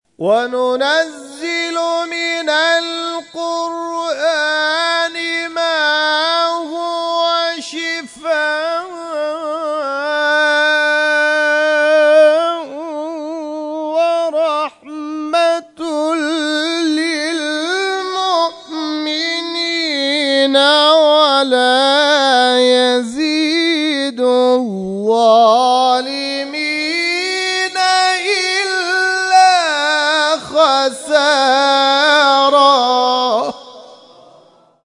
در ادامه قطعات تلاوت این کرسی‌های تلاوت ارائه می‌شود.